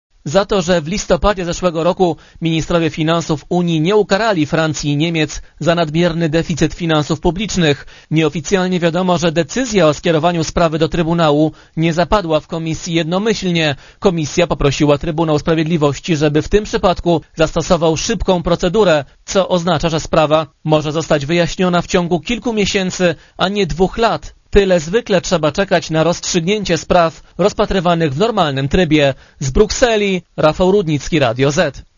Korespondencja z Brukseli (128Kb)